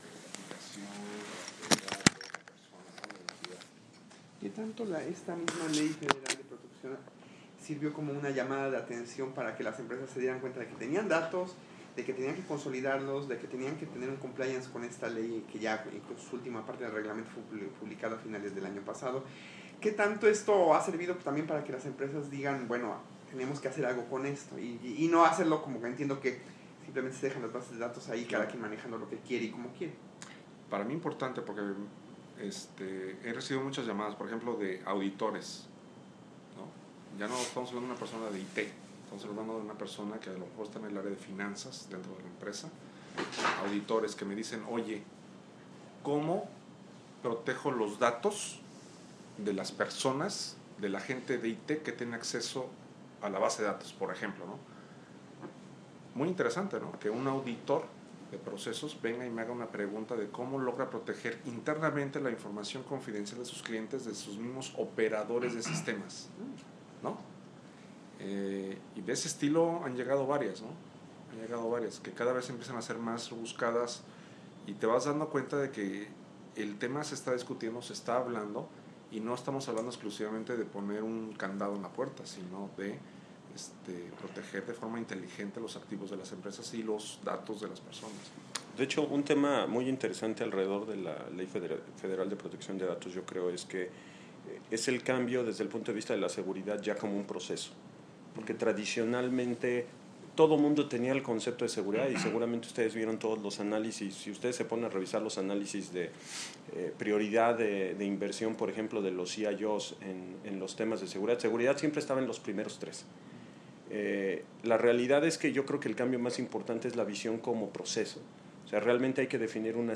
Cloud Computing y seguridad. Charla con ejecutivos de IBM México